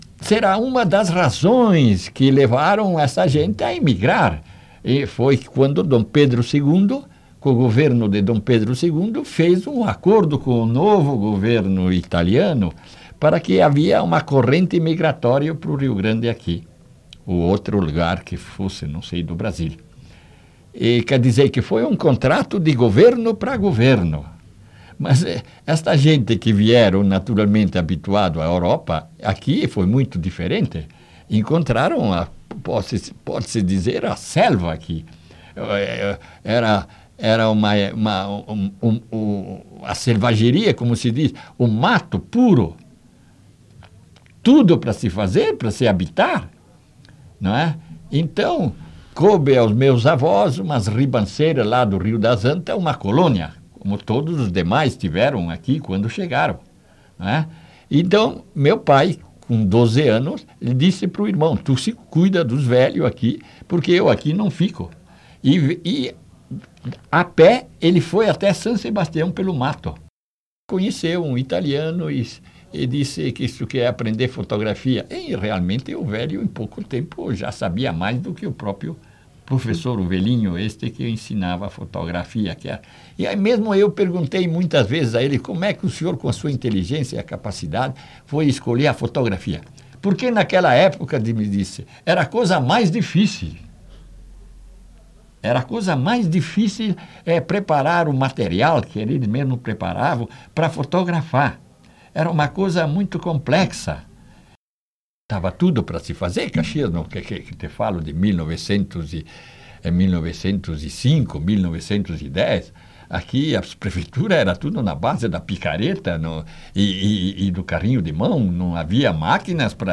Item AUD - Trecho de áudio da entrevista
Unidade Banco de Memória Oral